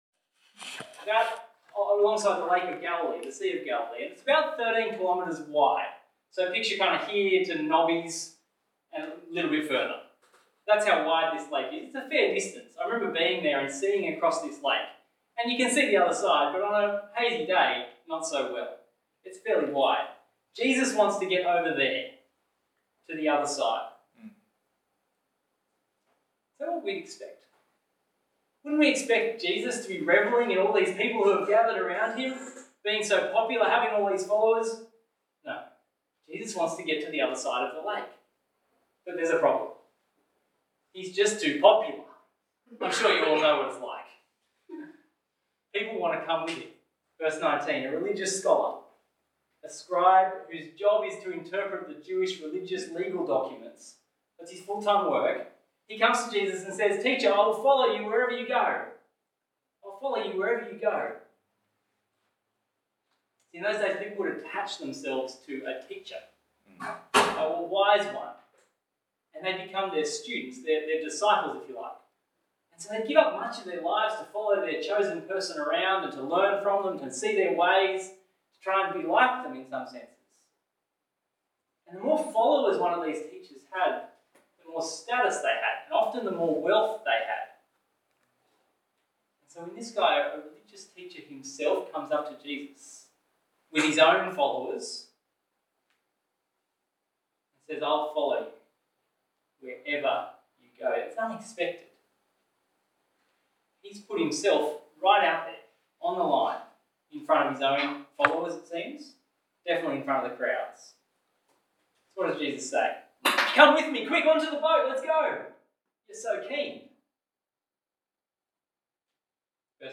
Passage: Matthew 8:18-27 Talk Type: Bible Talk